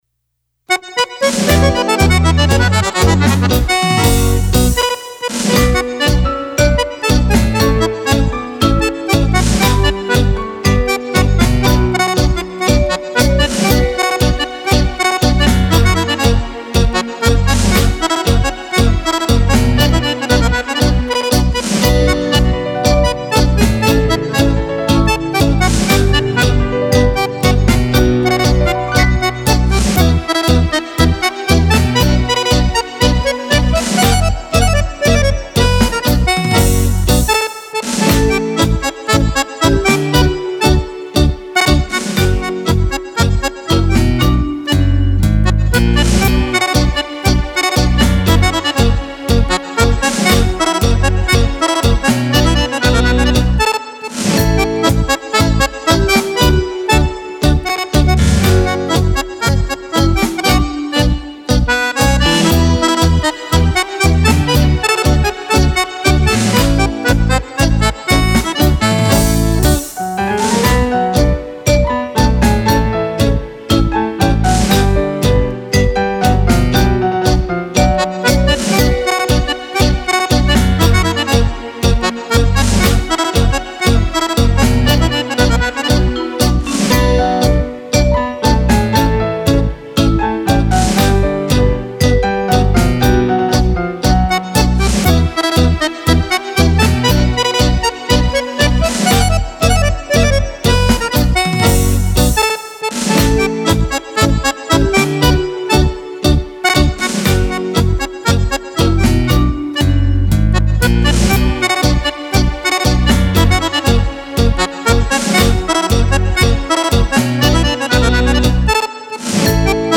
Tango
Album di ballabili  per Fisarmonica.